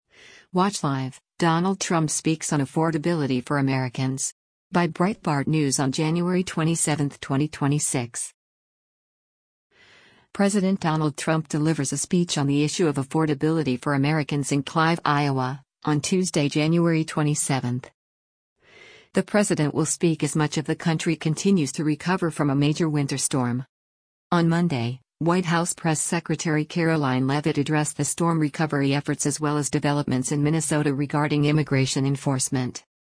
President Donald Trump delivers a speech on the issue of affordability for Americans in Clive, Iowa, on Tuesday, January 27.